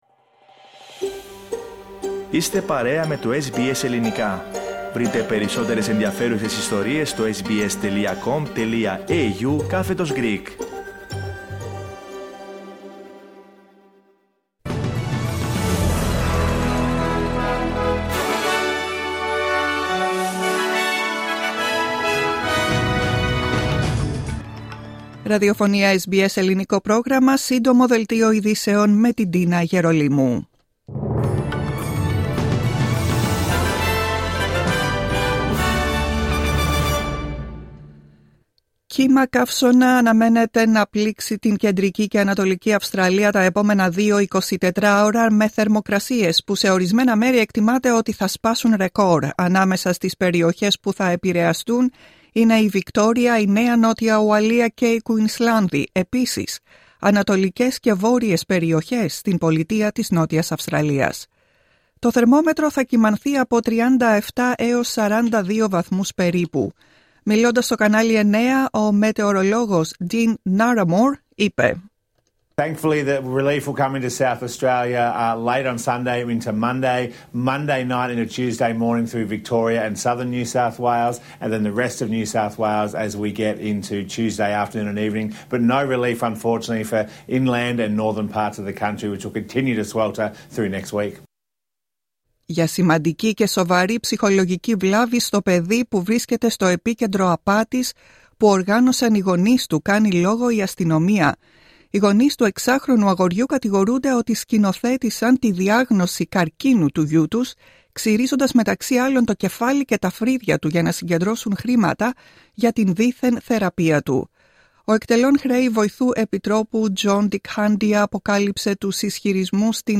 Δελτίο ειδήσεων Σάββατο 14 Δεκεμβρίου 2024
Ακούστε το δελτίο ειδήσεων του Ελληνικού Προγράμματος.